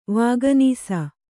♪ vāganīsa